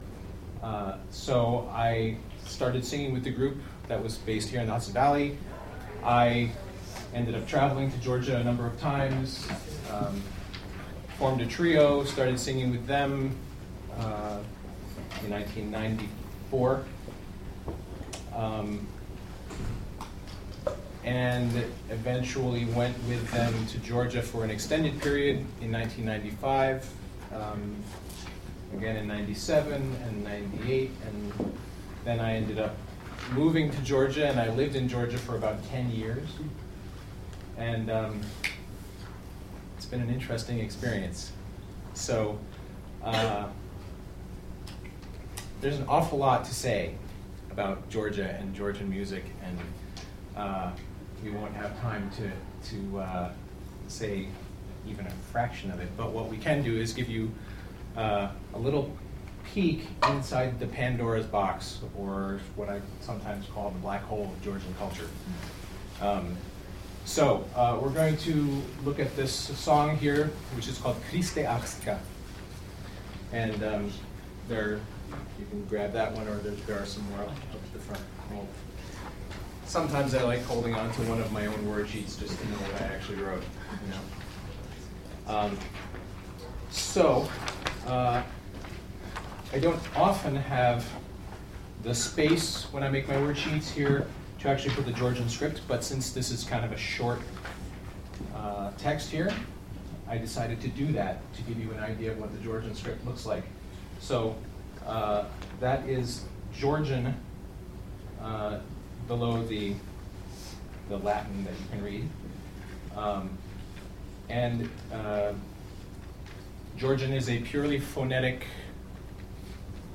Georgian Polyphony Workshop
at the Bard Sound Symposium